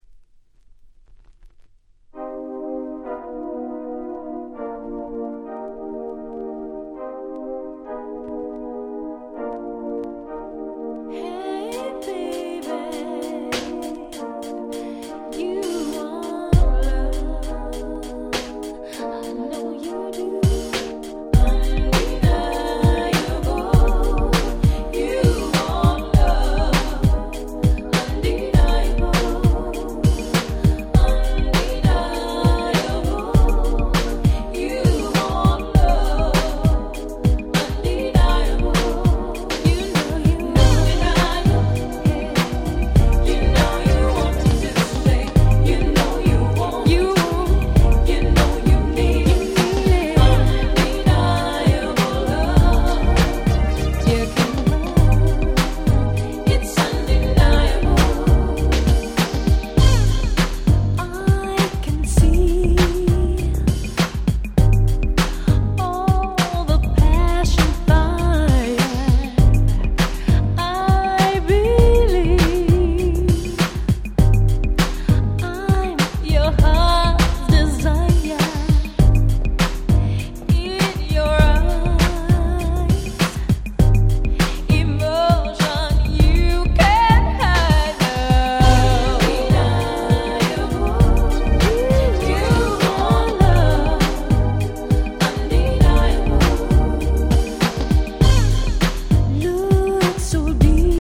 【Media】Vinyl 12'' Single (Promo)
95' Very Nice R&B !!